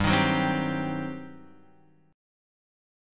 G Major 7th Suspended 4th Sharp 5th